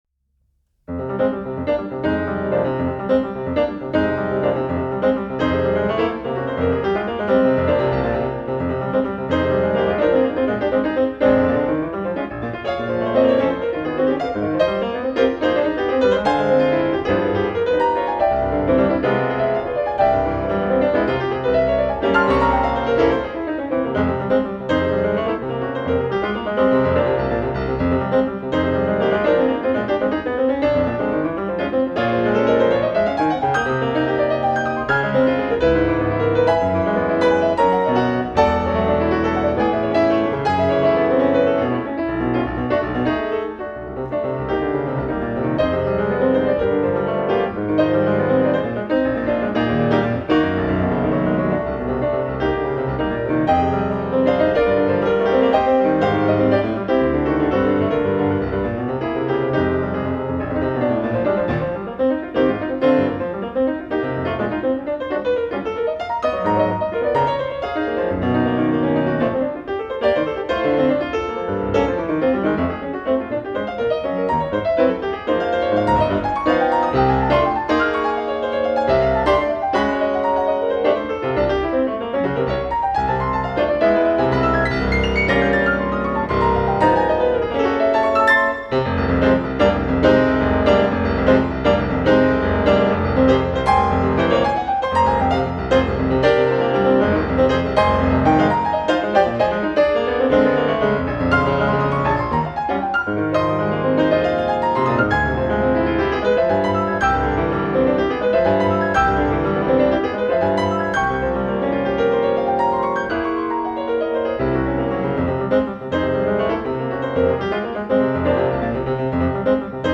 piano
punchy, impressionistic, groovy, funky